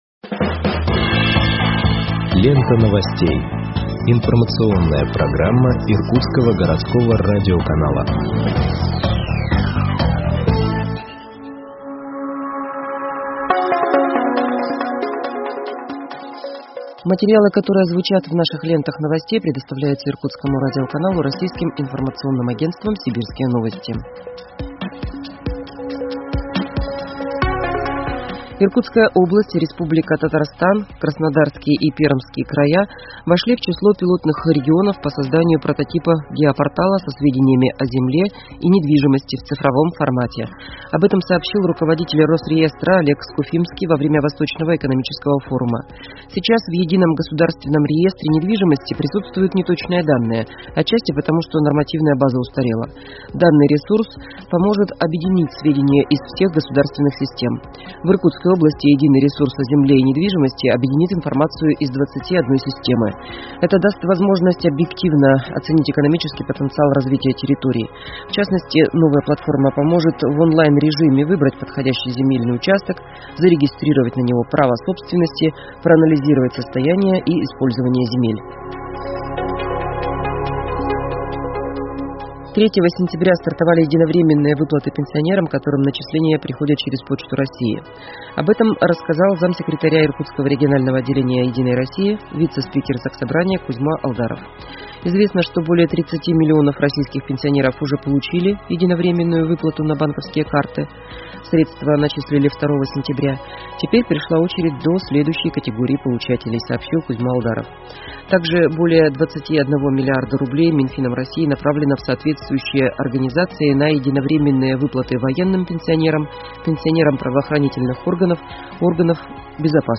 Выпуск новостей в подкастах газеты Иркутск от 08.09.2021 № 1